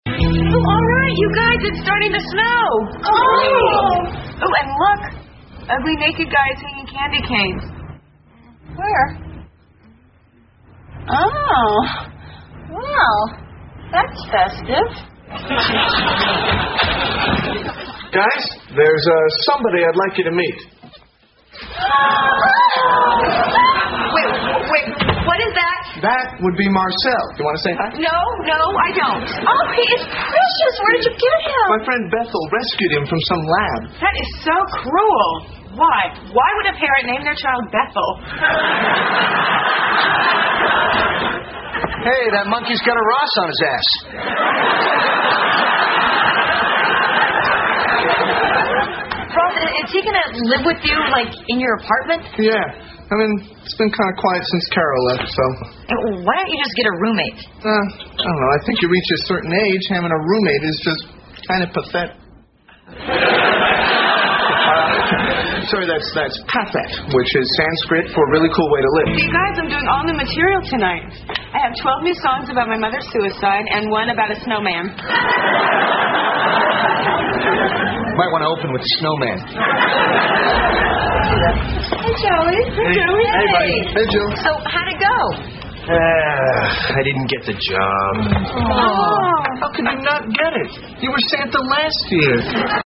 在线英语听力室老友记精校版第1季 第113期:猴子(1)的听力文件下载, 《老友记精校版》是美国乃至全世界最受欢迎的情景喜剧，一共拍摄了10季，以其幽默的对白和与现实生活的贴近吸引了无数的观众，精校版栏目搭配高音质音频与同步双语字幕，是练习提升英语听力水平，积累英语知识的好帮手。